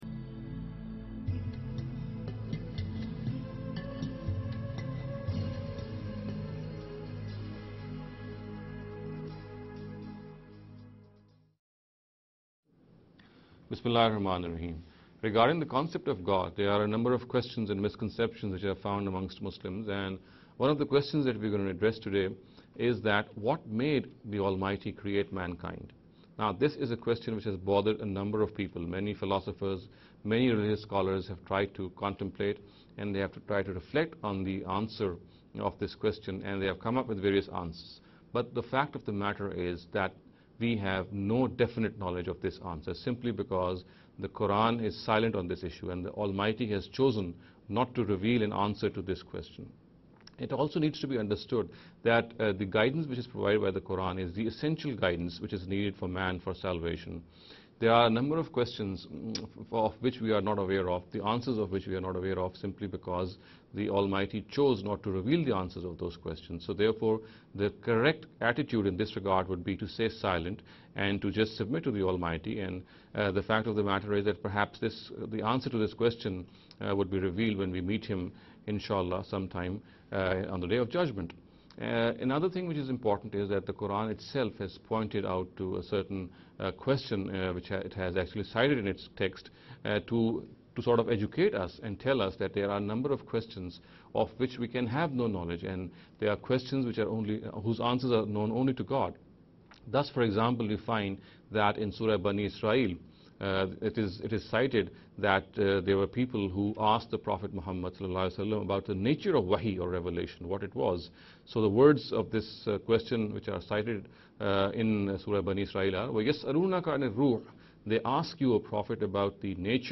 This lecture series will deal with some misconception regarding the concept of God.